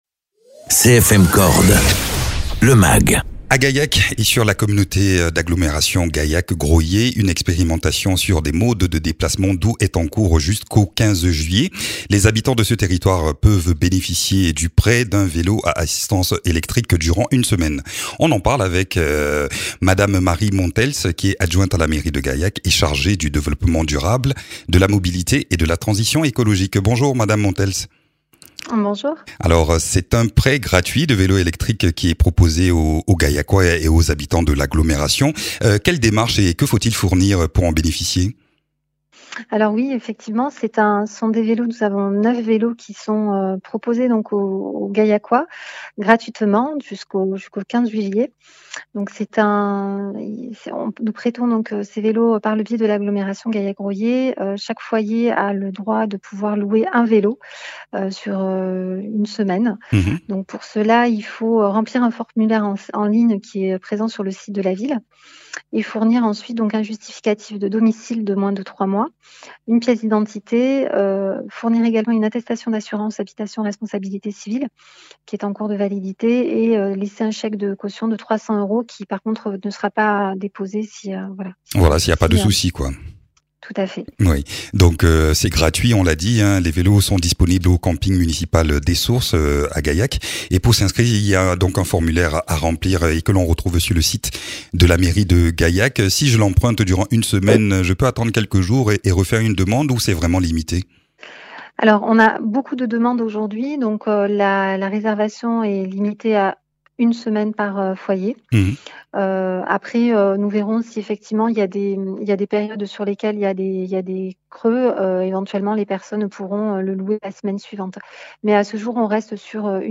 Interviews
Invité(s) : Marie Montels, élue de la ville de Gaillac en charge du développement durable, de la mobilité et de la transition écologique.